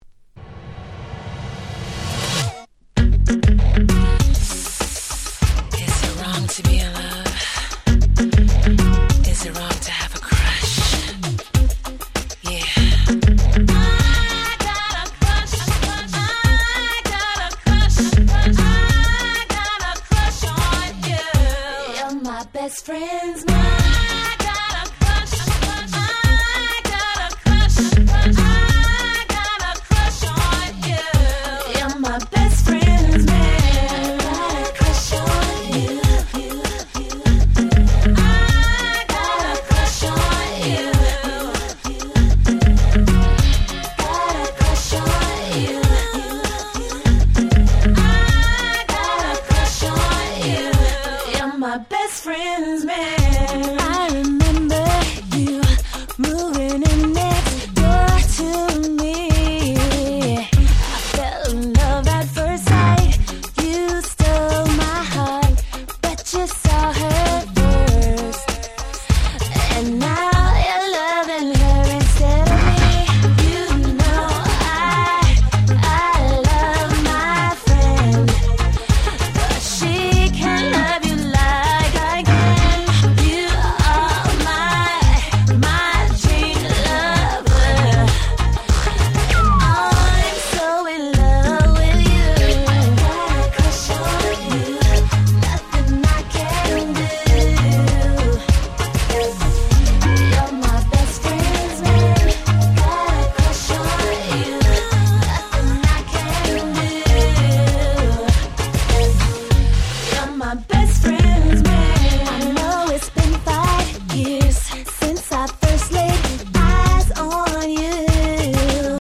03' Nice R&B !!